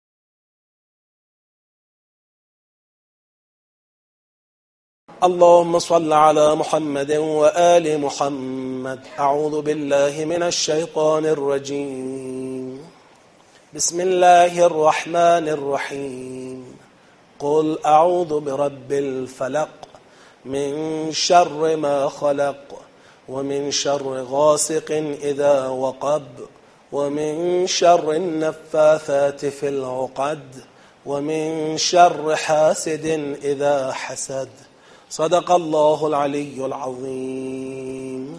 قرائت تدبری سوره فلق
این قرائت مربوط به دوره آموزش سطح یک تدبر در قرآن کریم است که پاییز ۹۸ در شهرک شهید محلاتی برگزار شده است.
56-1-قرائت-تدبری-سوره-فلق.mp3